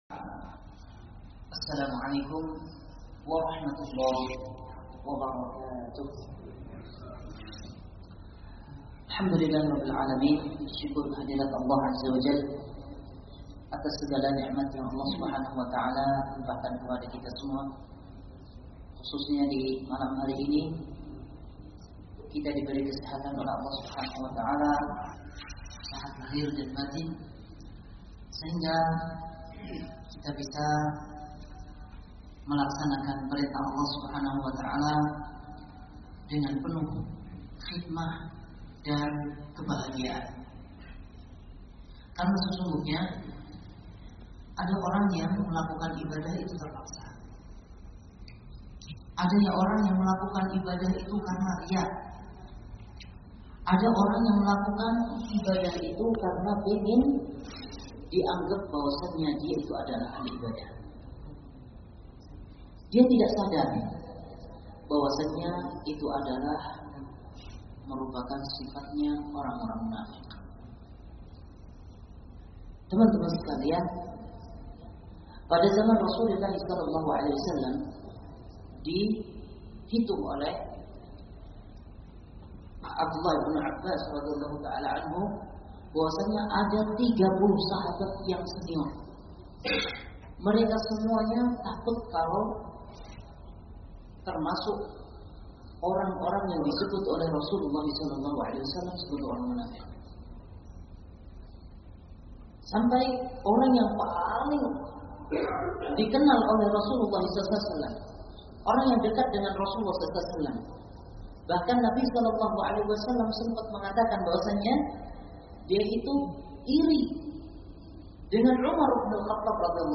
Kajian ini merupakan kajian rutin Messaied setiap Kamis malam.